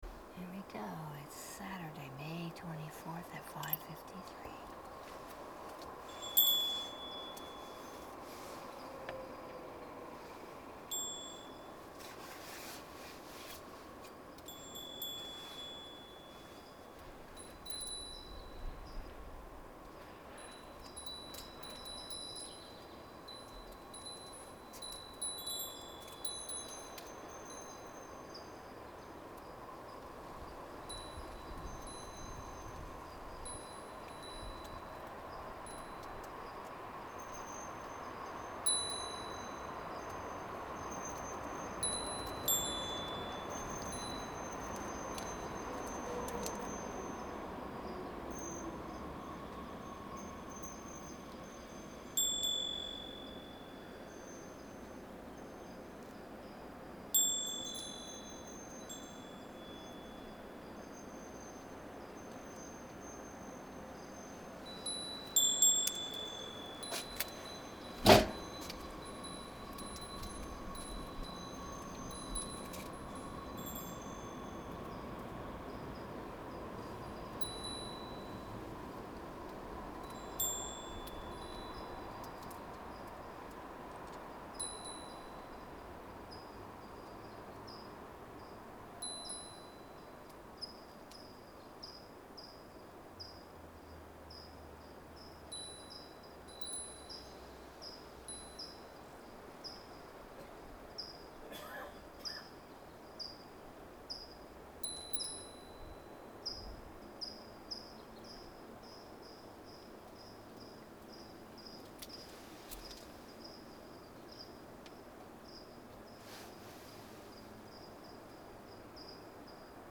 5/24 AM One Streetcar Comes and Goes